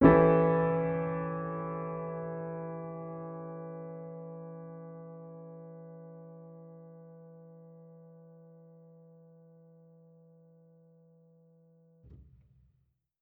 Index of /musicradar/jazz-keys-samples/Chord Hits/Acoustic Piano 2
JK_AcPiano2_Chord-Em13.wav